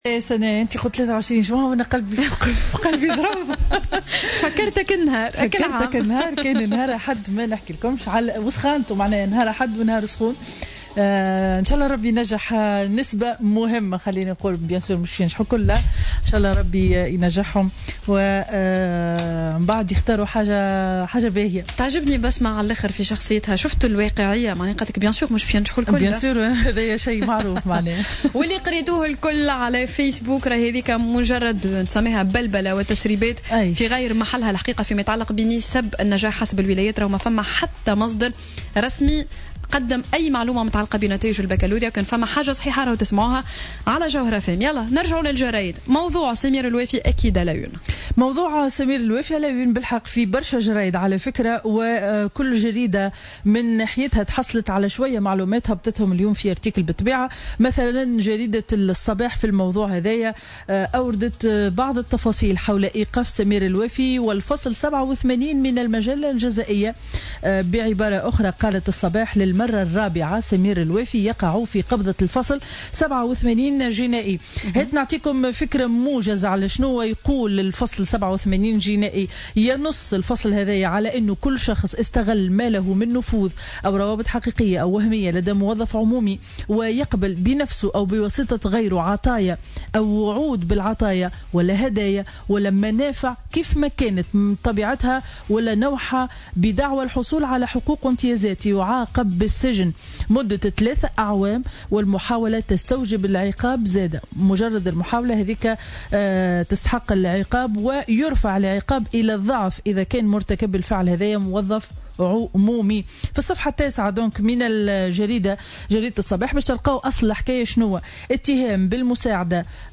Revue de presse du mercredi 21 juin 2017